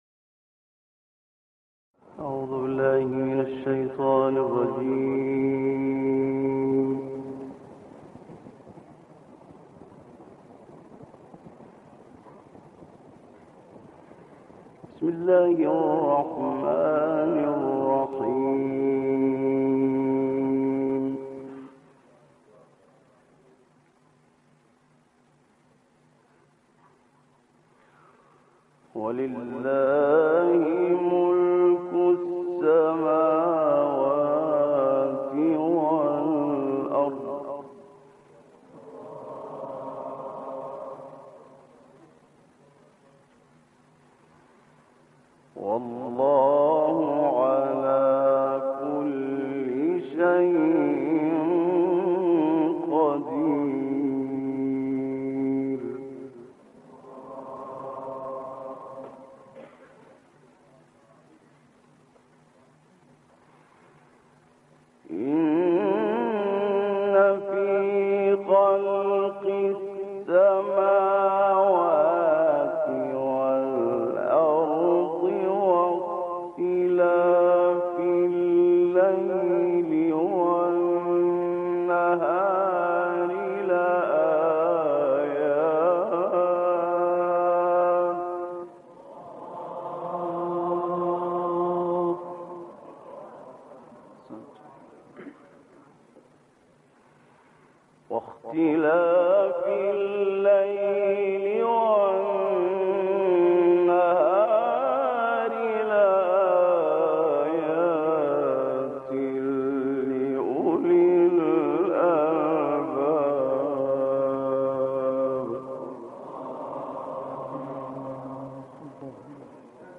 تلاوت زیبای